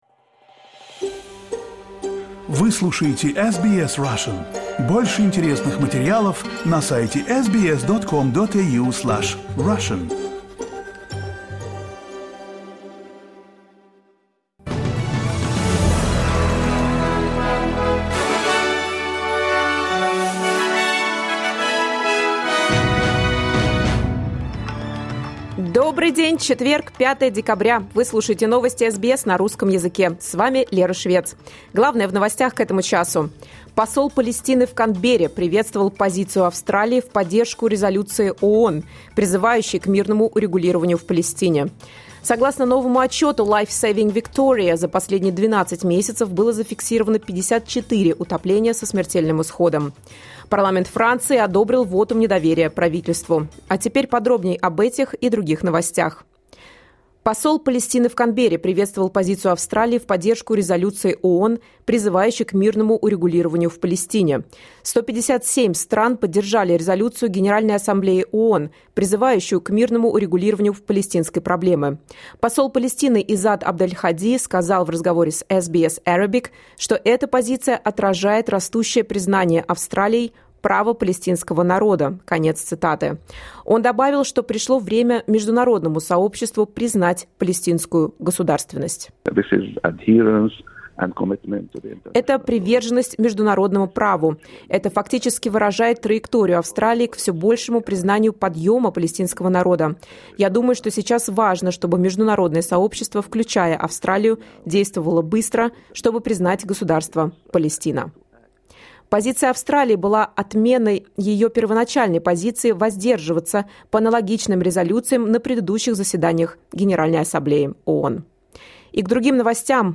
Новости SBS на русском языке — 05.12.2024